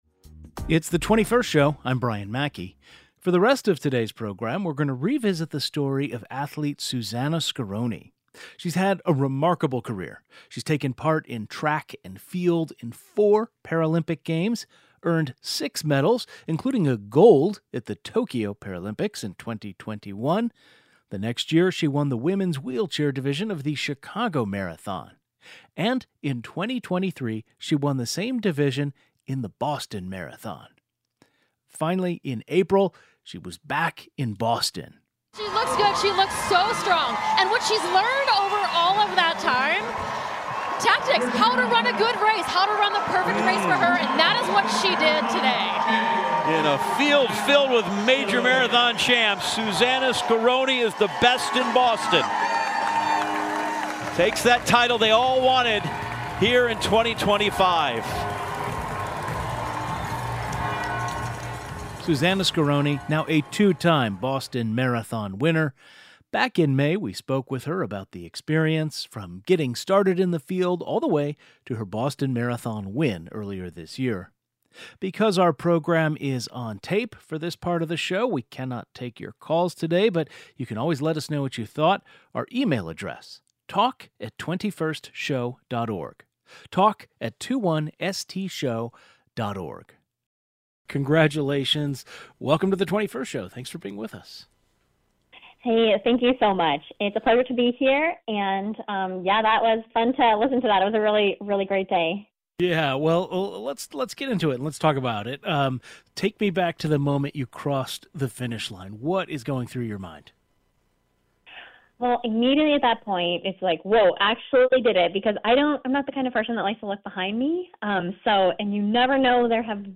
Athlete and University of Illinois alumna Susannah Scaroni talks about winning the women's wheelchair division at the Boston Marathon and other highlights from her career.